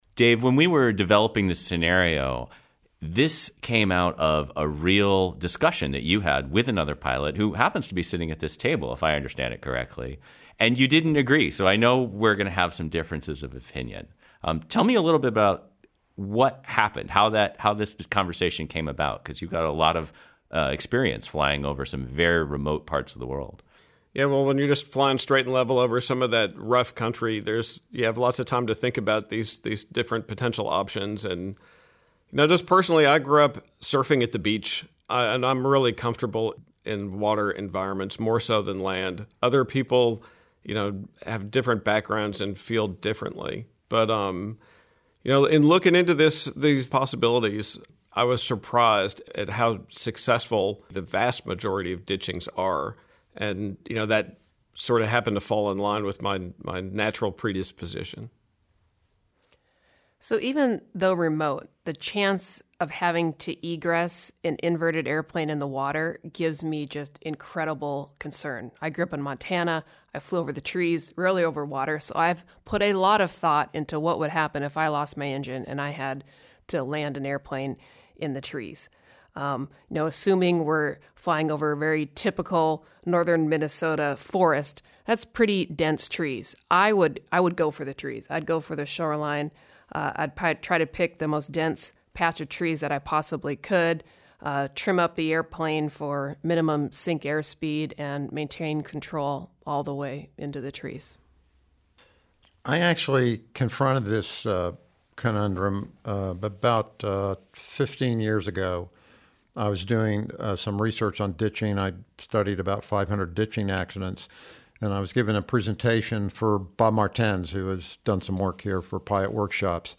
Feet Wet or Dry_Roundtable.mp3